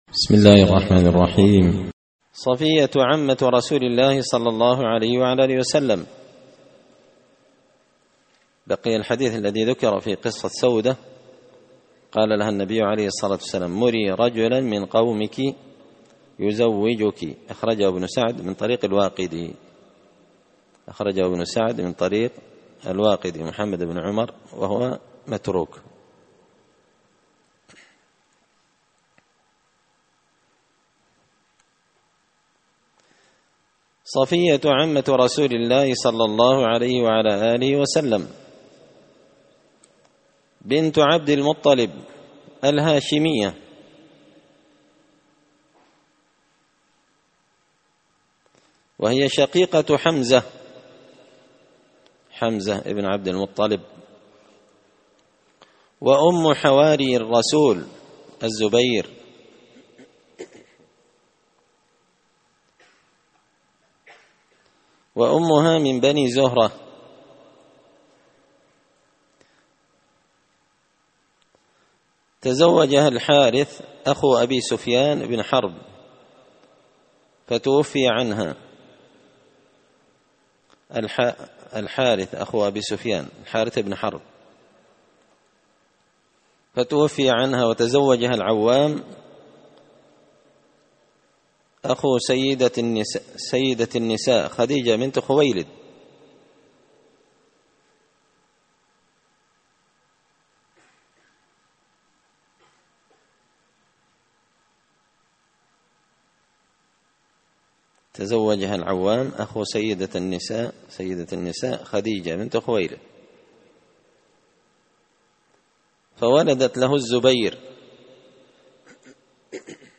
قراءة تراجم من تهذيب سير أعلام النبلاء
دار الحديث بمسجد الفرقان ـ قشن ـ المهرة ـ اليمن